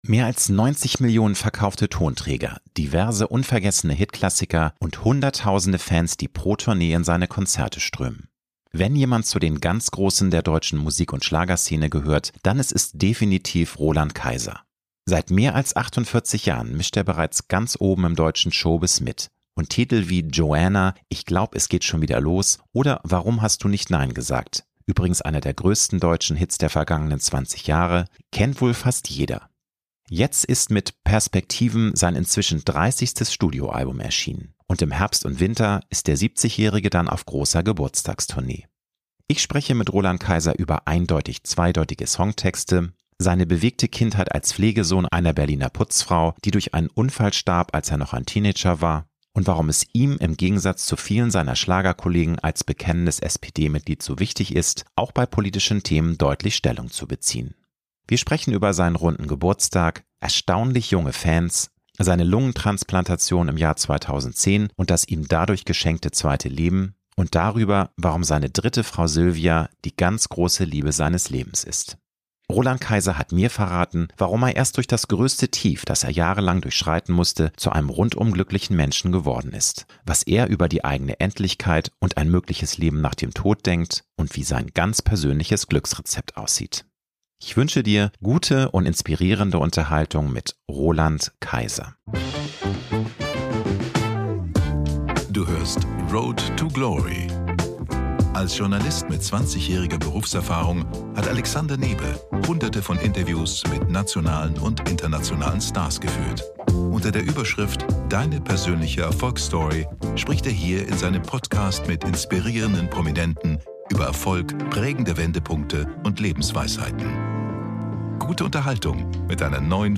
Ich spreche mit Roland Kaiser über eindeutig zweideutige Songtexte, seine bewegte Kindheit als Pflegesohn einer Berliner Putzfrau, die durch einen Unfall starb, als er noch ein Teenager war und warum es ihm im Gegensatz zu vielen seiner Schlagerkollegen als bekennendes SPD-Mitglied so wichtig ist, auch bei politischen Themen deutlich Stellung zu beziehen.